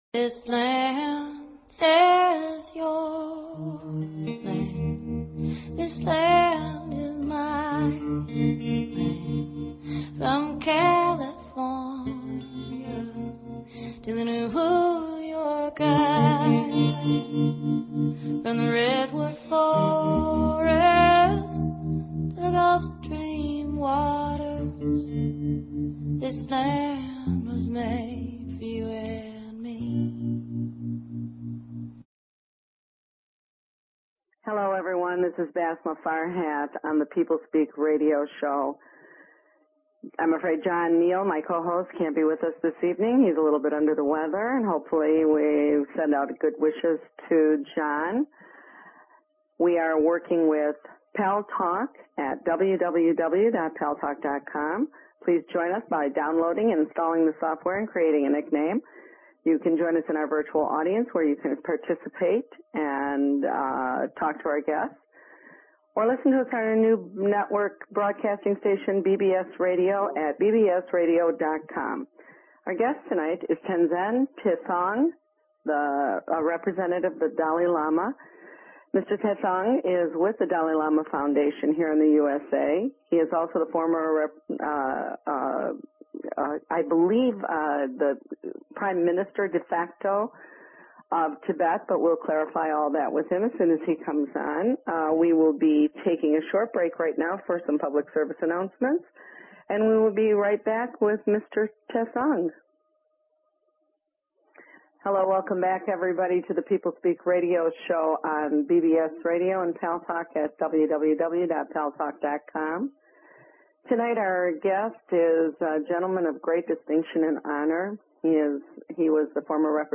Talk Show Episode, Audio Podcast, The_People_Speak and Tenzin Tethong on , show guests , about , categorized as